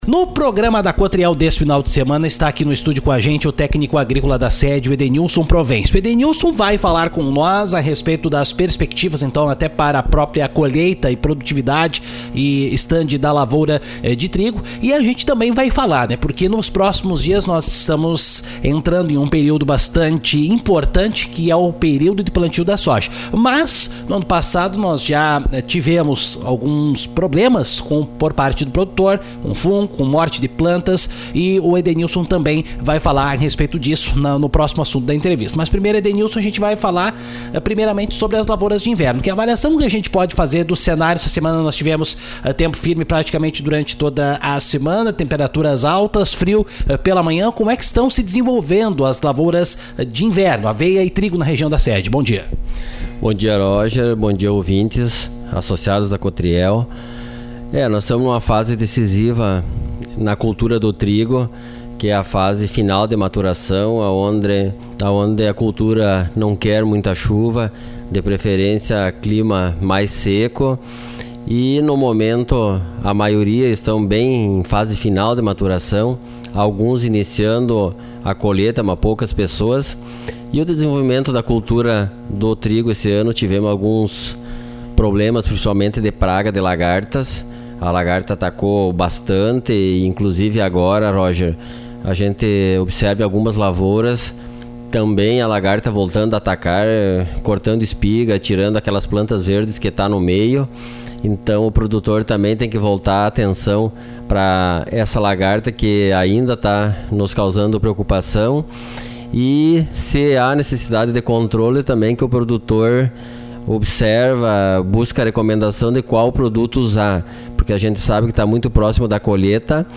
Ele alerta para que os produtores devem observar condições mínimas ideais para a semeadura da soja, com temperatura de solo superior a 25°C e temperatura ambiente em torno de 30°C. Ouça aqui a entrevista.